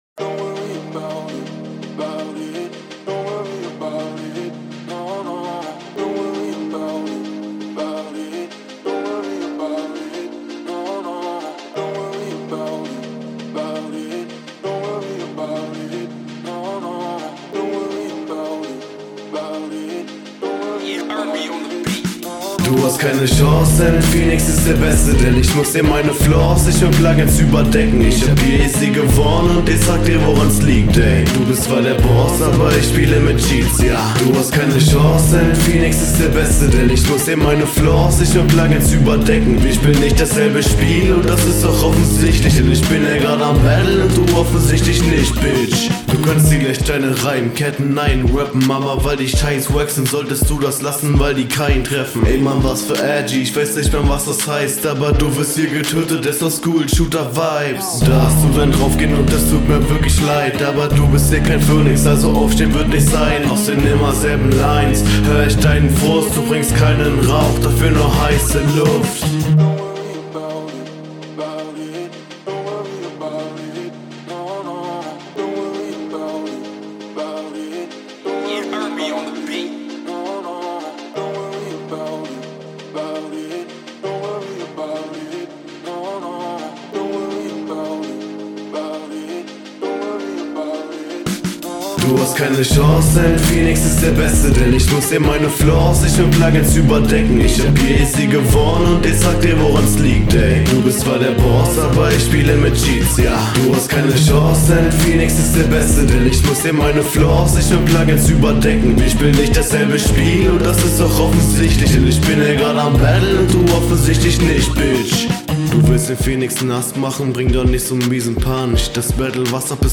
Soundqualität ähnlich, aber ein ticken schlechter. Mix etwas unangenehm in den Ohren.
Die Hook Flowt gut, der Part stottert n bissl vor sich …